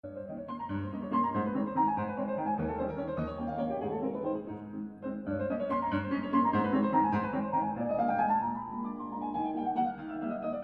Virtual ambisonic rendering corresponding to the movies in Table 7.
Train yourself with the reference file first (single source r=0%) if you have not already done so, in order to really imagine that the source is drawing a circular (anti-clock wise), horizontal trajectory around you.